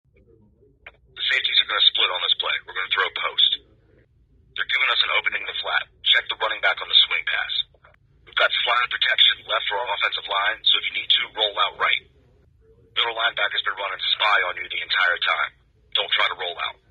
We connected our ProCom helmet receiver to a separate headset system so you can hear the clarity for yourself. No gimmicks—just clean, secure C2P coach-to-player audio under real-world conditions.
ProCom-AMR-with-other-Headset-System.mp3